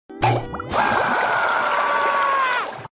Mr Krabs Screams Through His Eyeballs Sound Button - Free Download & Play